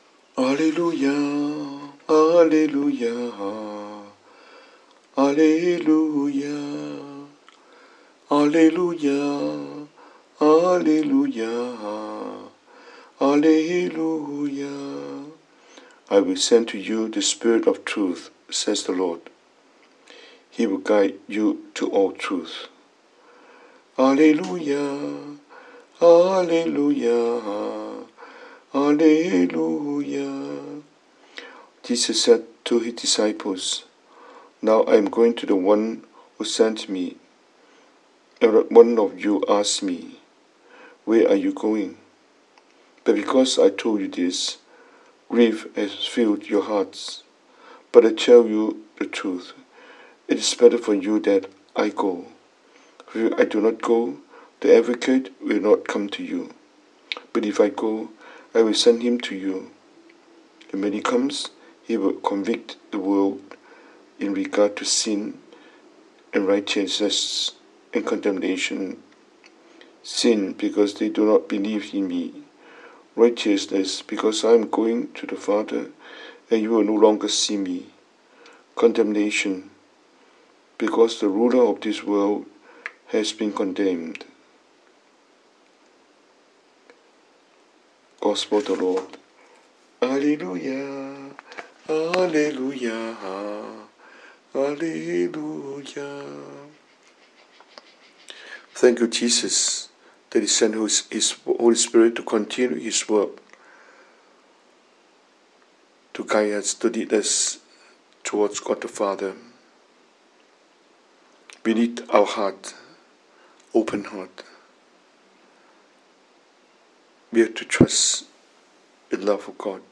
中文講道,